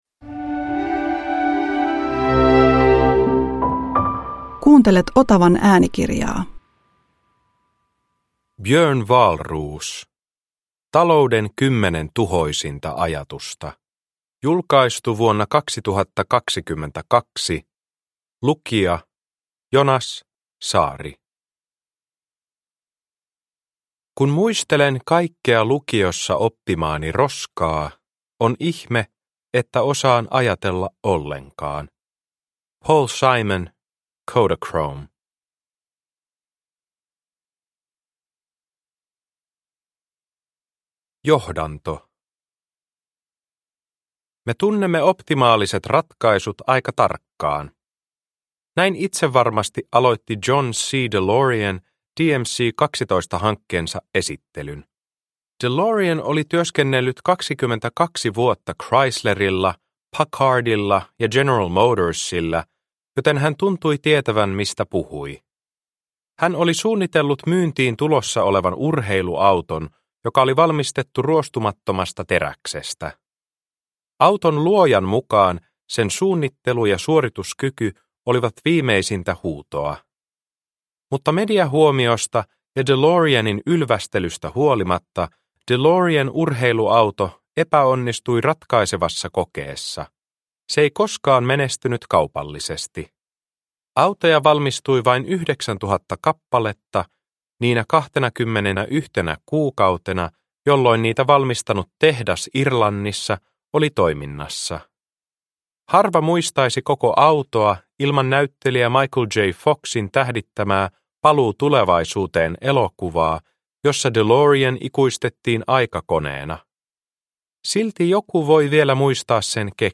Talouden kymmenen tuhoisinta ajatusta – Ljudbok – Laddas ner